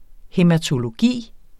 Udtale [ hεmatoloˈgiˀ ]